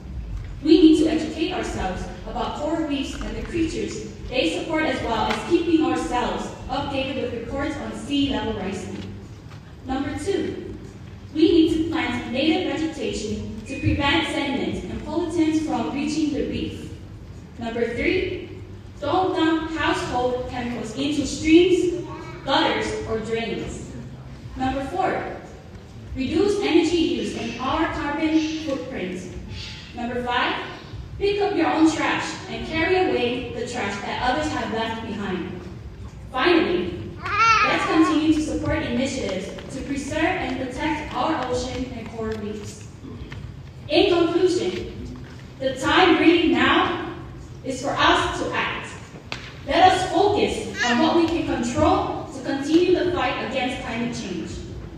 They started with prepared presentations on their selected topics, and then they answered judges questions.
Here are excerpts from the four contestants on the topics of education, drugs, environment and tourism.